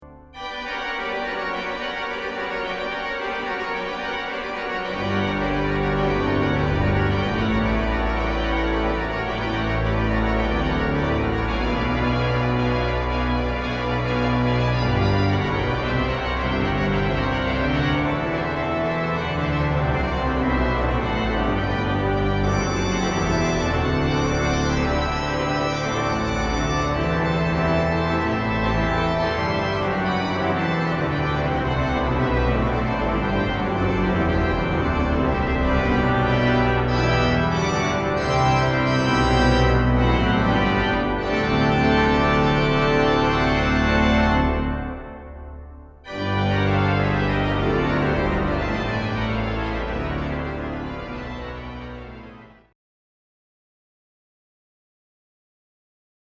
näyte teoksen finaalista!